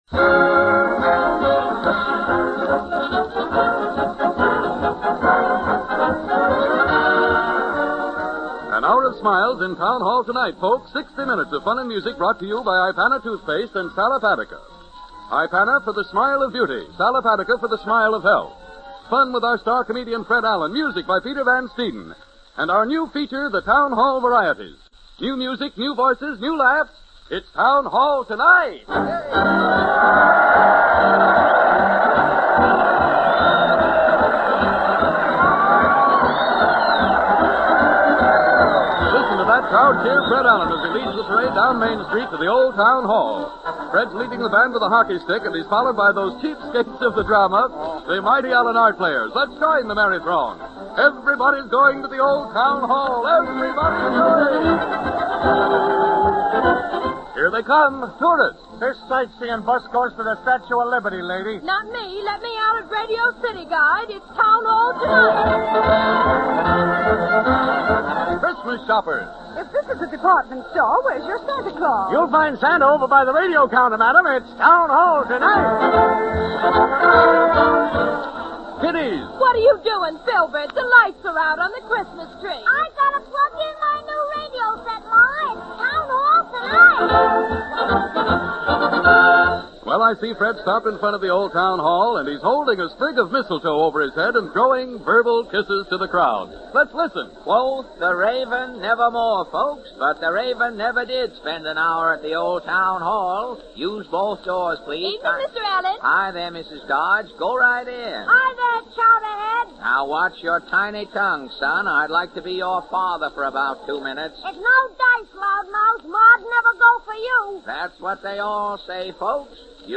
Fred Allen - Town Hall Tonight - Christmas 1936 - Past Daily Holiday Pop Chronicles - Originally broadcast December 23, 1936.
Switching over to Holiday mode today and starting off with a Christmas program from Fred Allen, first aired on December 23, 1936.
Fred Allen was one of the biggest names in radio comedy in the 1930s and 1940s, whose influence has inspired generations of comedians after his death.